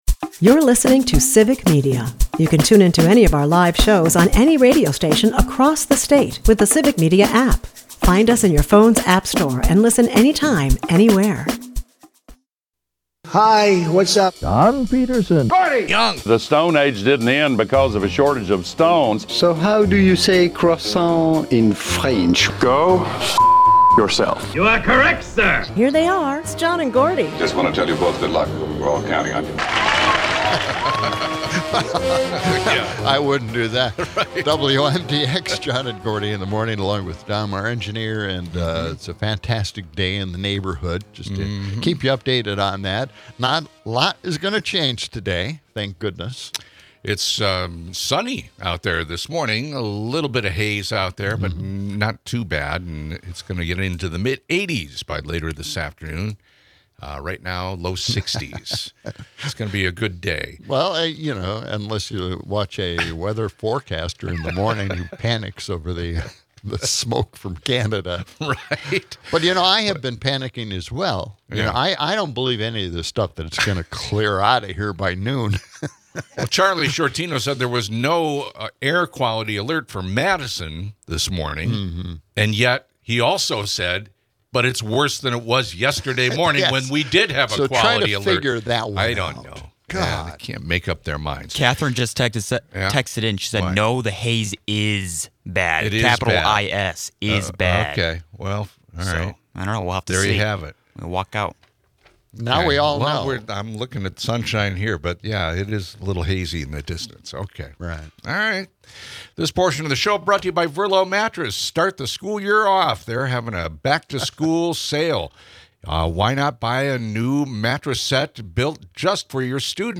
They spotlight Gen Z as the torchbearers for change and slam current policies that neglect farmers, contrasting U.S. woes with Canadian successes. A lively mix of politics, weather updates, and generational musings, with a dash of humor and local flair.